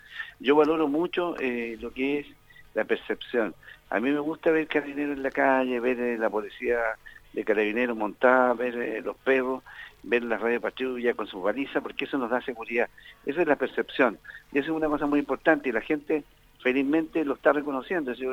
En este respecto, el Gobernador de la Provincia de Osorno, Daniel Lilayú, destacó la labor realizada por Carabineros en la zona, en favor de la seguridad de la comunidad.